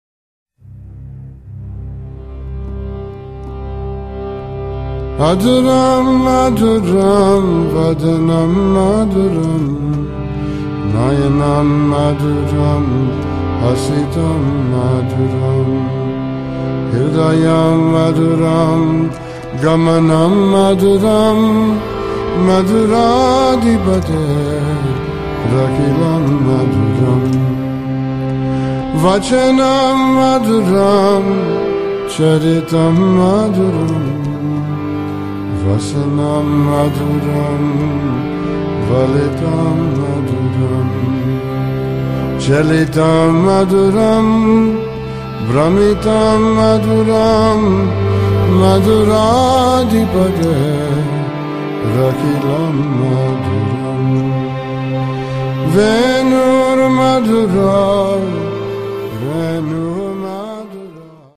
These verses are sung and honored throughout India.